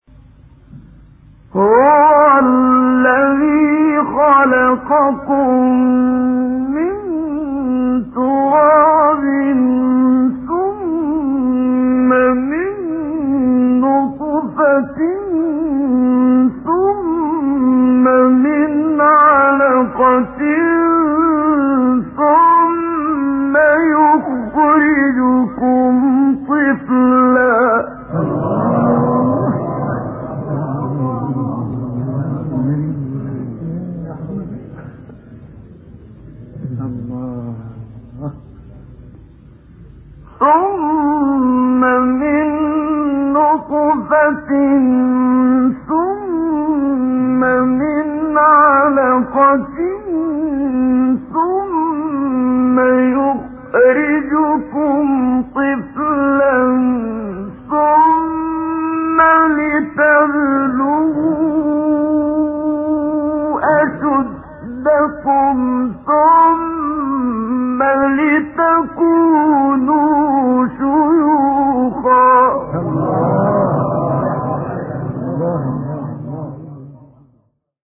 گروه شبکه اجتماعی: نغمات صوتی از قاریان ممتاز مصری ارائه می‌شود.